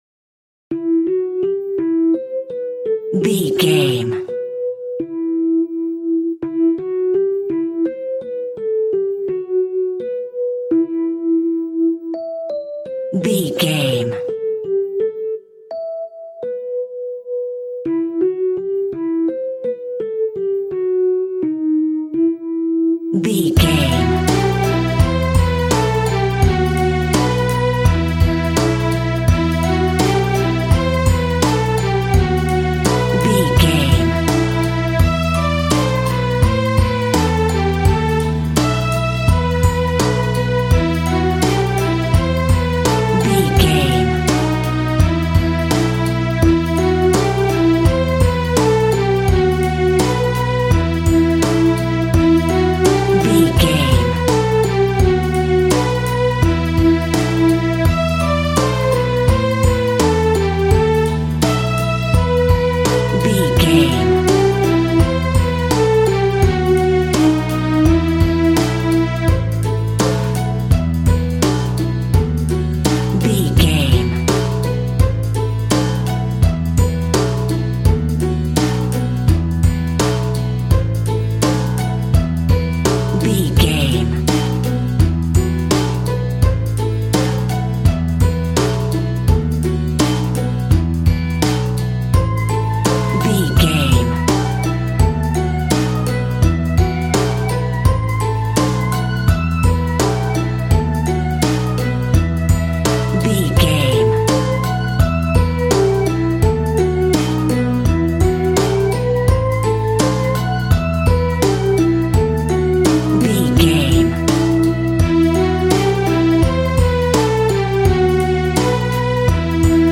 Aeolian/Minor
instrumentals
fun
childlike
cute
happy
kids piano